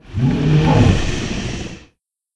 c_hydra_hit2.wav